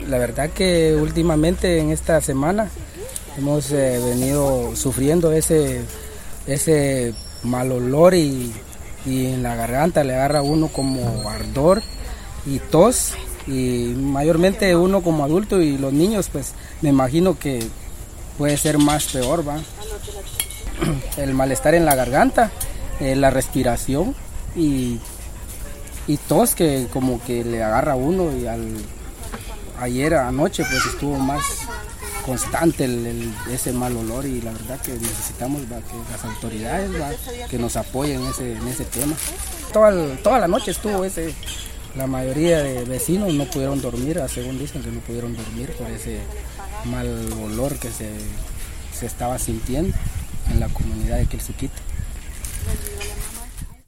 Las voces de la aldea El Suquite.